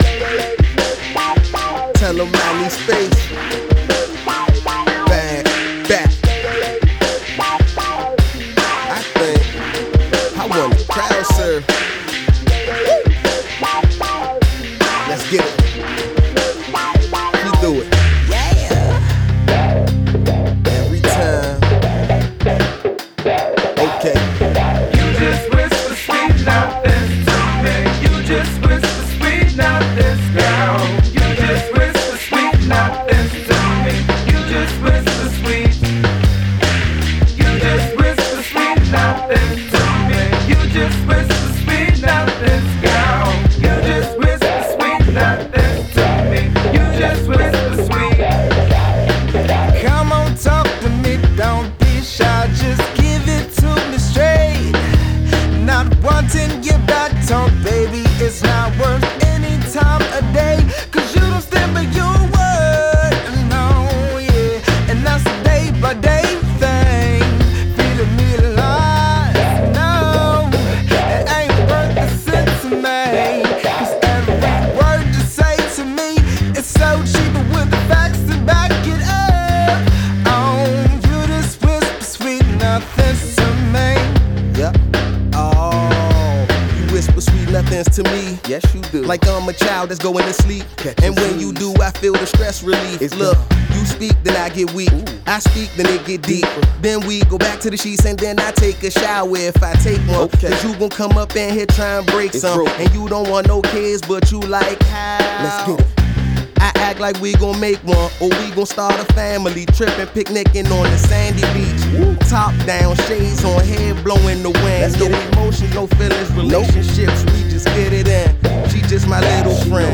Hip-Hop Rock